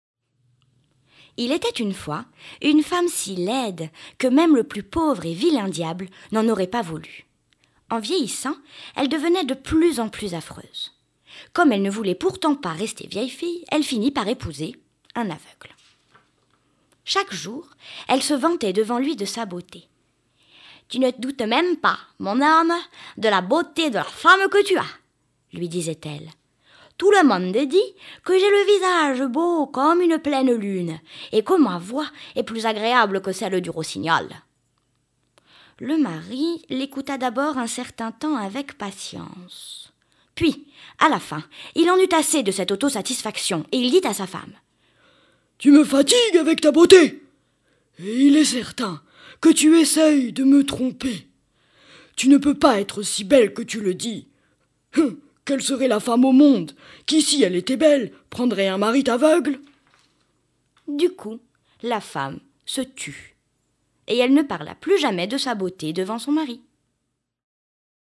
Sprecherin französisch.
Sprechprobe: eLearning (Muttersprache):